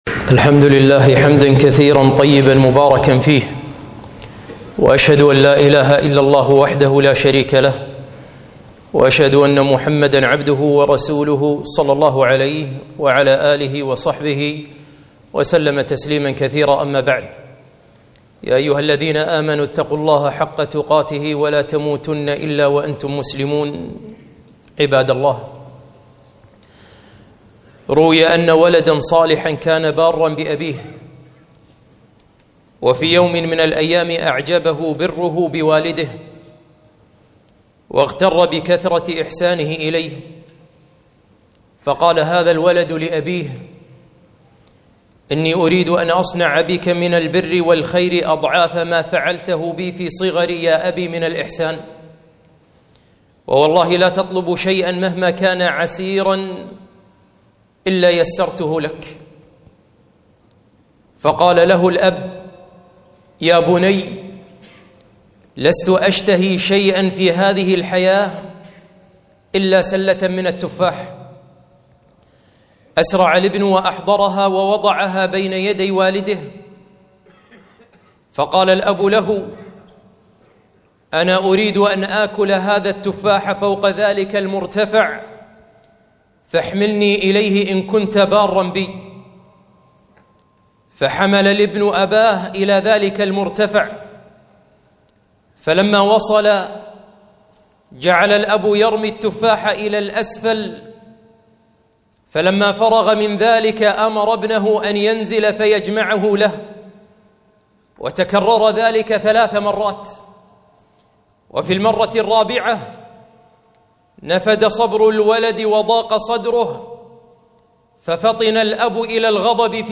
خطبة - الأب وما أدراك ما الأب